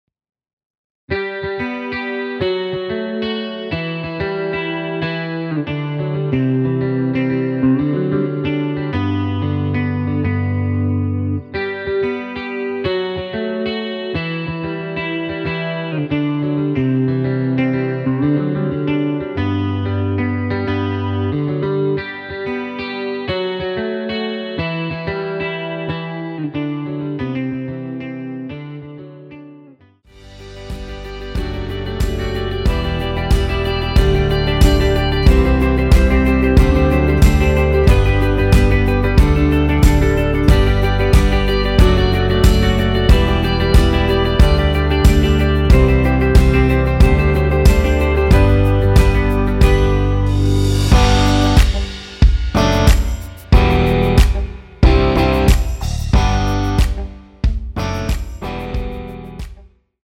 원키에서(-2)내린 (2절 삭제)하고 진행 되는 MR입니다.
앞부분30초, 뒷부분30초씩 편집해서 올려 드리고 있습니다.
중간에 음이 끈어지고 다시 나오는 이유는